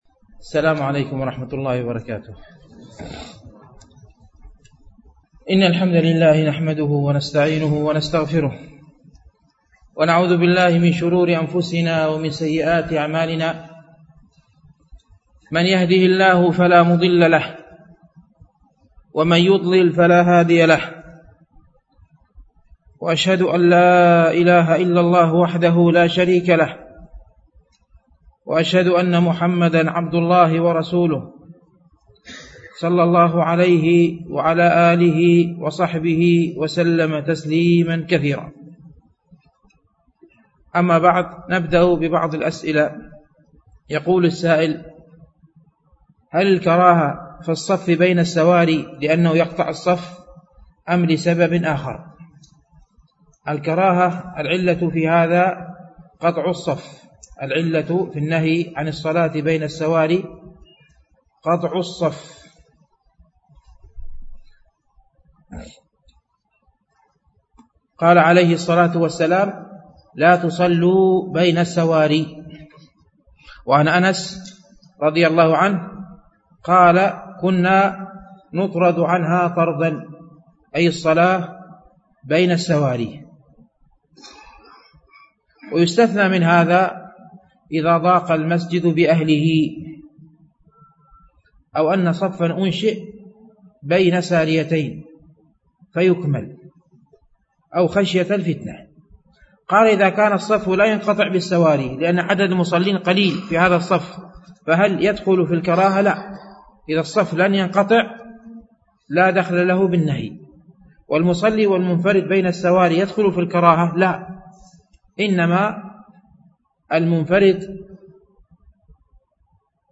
شرح رياض الصالحين - الدرس السادس والسبعون بعد المئة